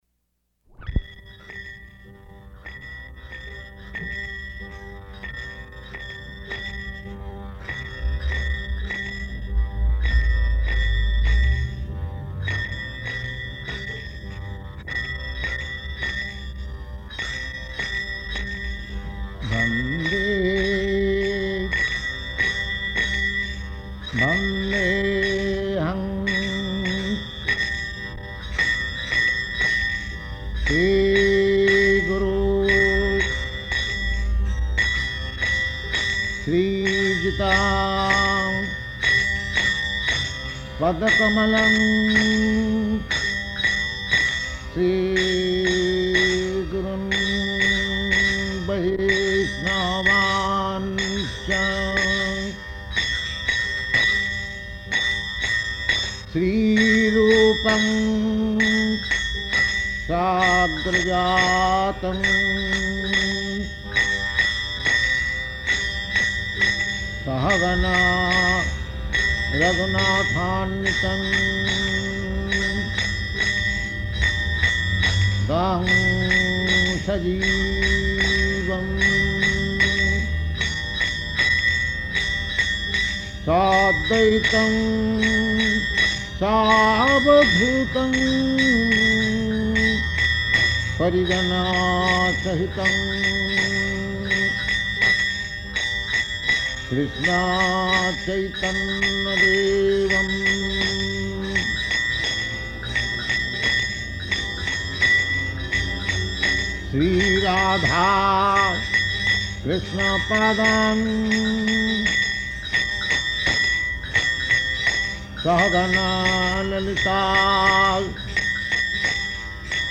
Bhagavad-gītā 3.17–20 --:-- --:-- Type: Bhagavad-gita Dated: May 27th 1966 Location: New York Audio file: 660527BG-NEW_YORK.mp3 Prabhupāda: [leads kīrtana ] [ prema-dhvani ] .